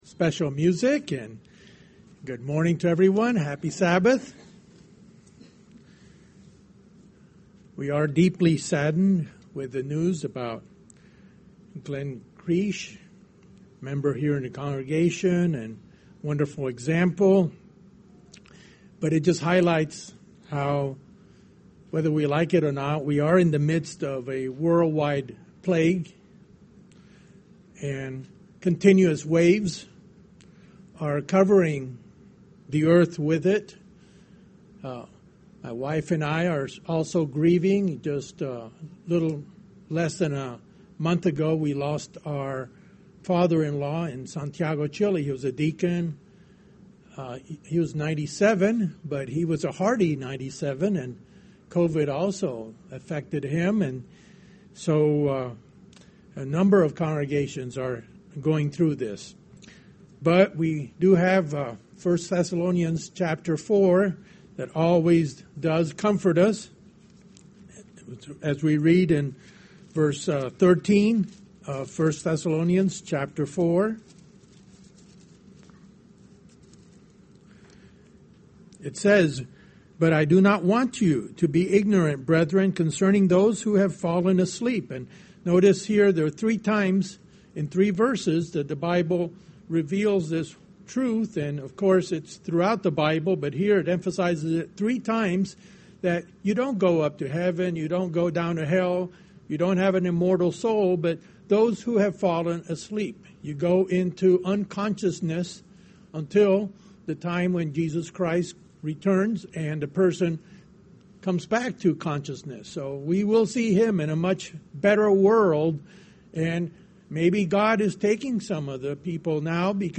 Given by visiting Council of Elders member.